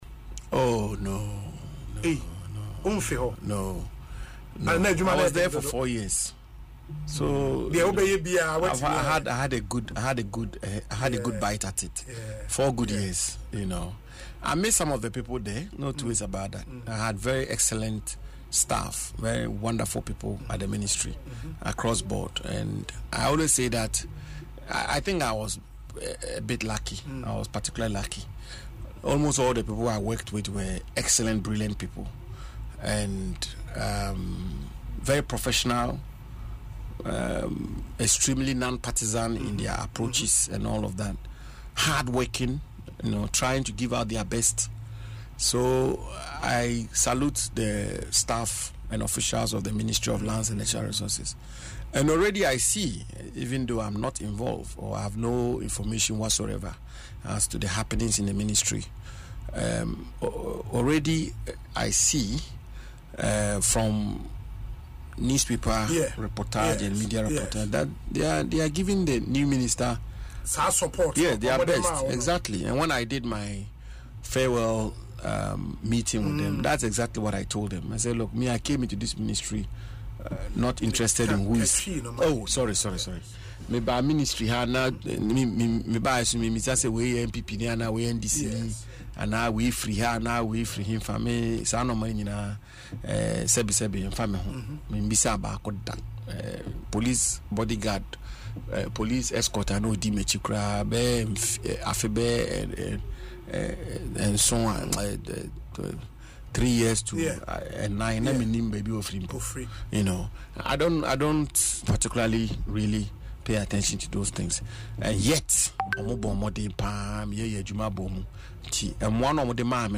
In an interview on Asempa FM’s Ekosii Sen, the Damongo Member of Parliament (MP) revealed that his time at the ministry is now behind him.